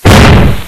New Backfire Sounds Replacing Dump-Valve Sounds
i've attached preview mp3s along with it but make sure you turn your speakers down before opening them because they are VERY LOUD. for some reason it's not as loud in lfs.
the 2nd sounds like a bomb going off..